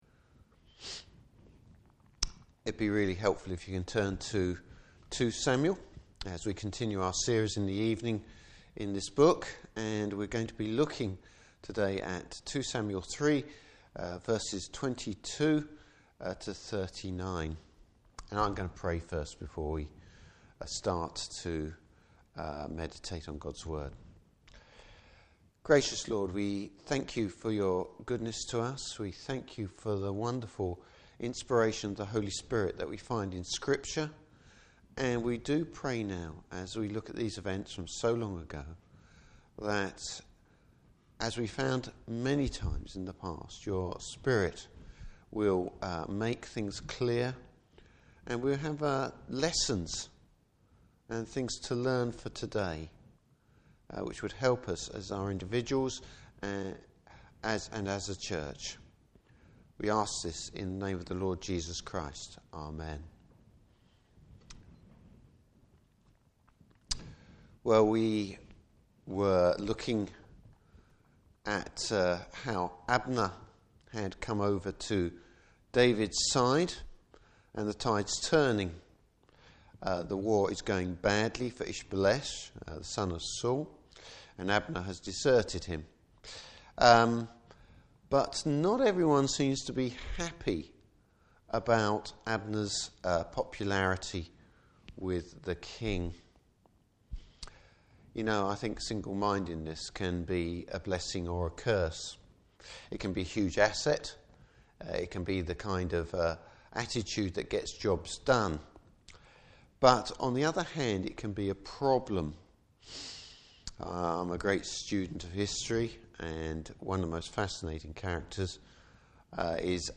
Service Type: Evening Service David’s wise and diplomatic approach.